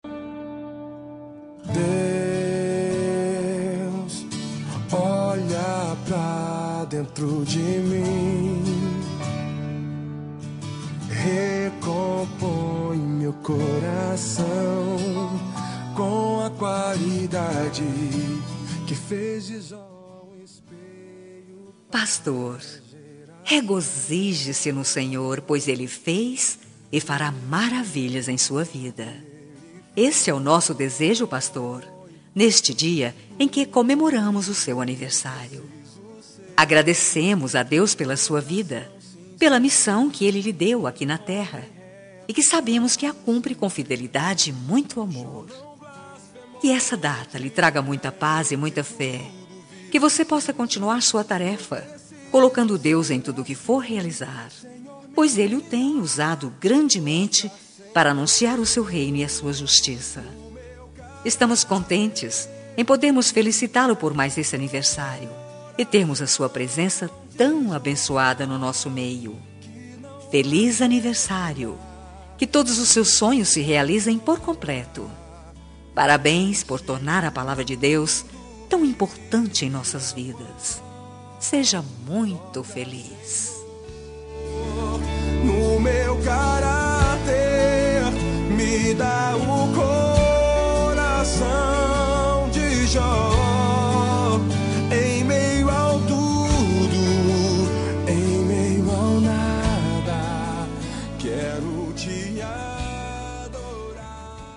Aniversário de Pastor – Voz Feminina – Cód: 5495